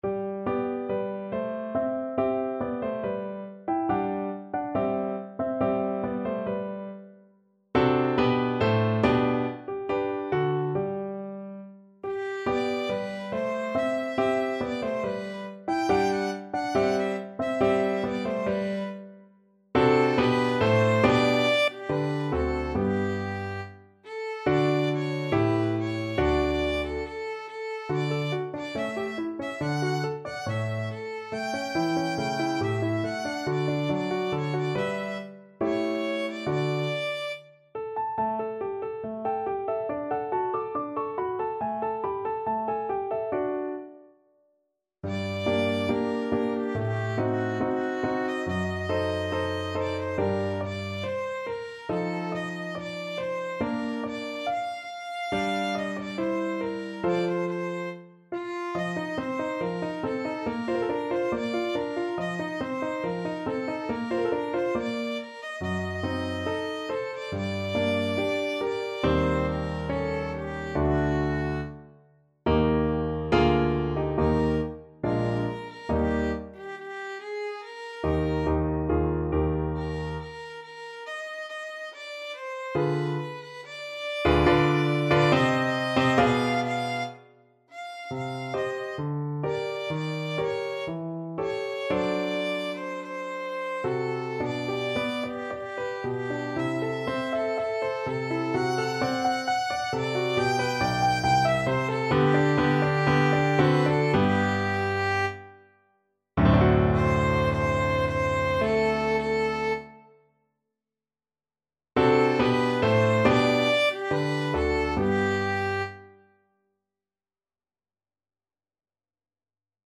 Classical Mozart, Wolfgang Amadeus Das Veilchen, K.476 Violin version
2/4 (View more 2/4 Music)
= 70 Allegretto
G major (Sounding Pitch) (View more G major Music for Violin )
Classical (View more Classical Violin Music)